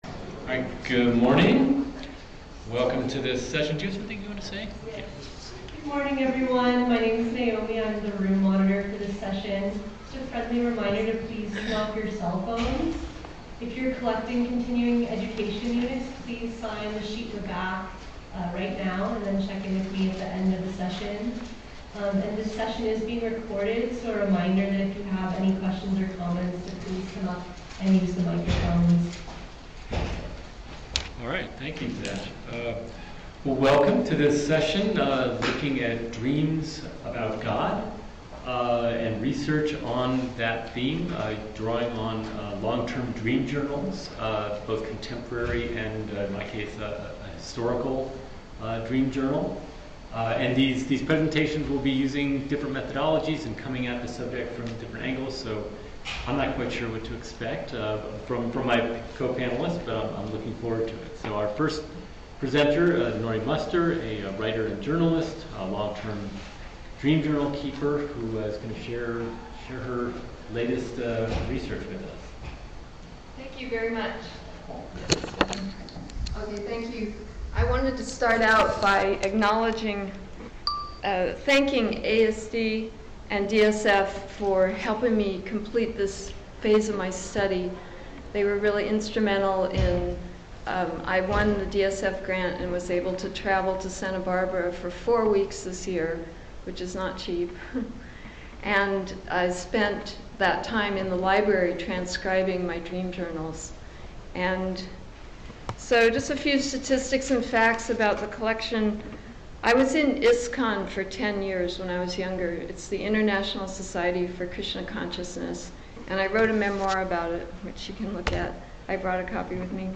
panel discussion